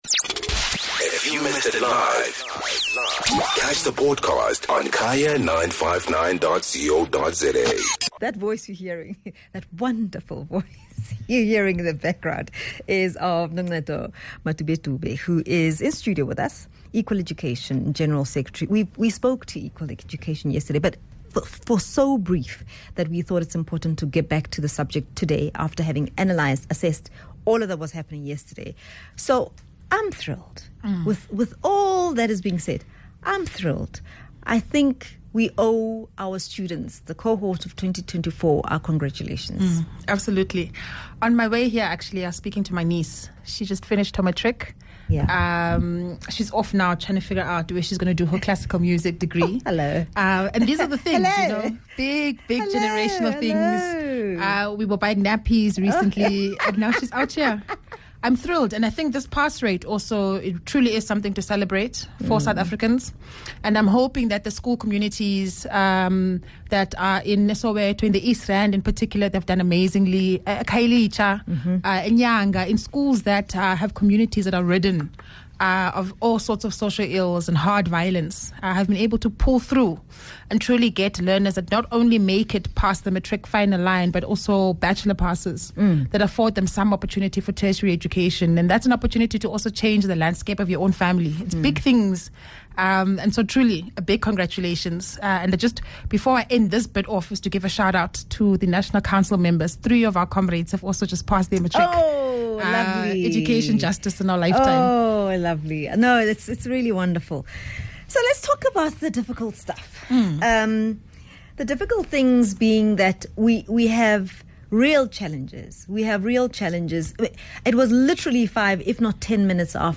14 Jan Discussion: SA education, matriculants 2024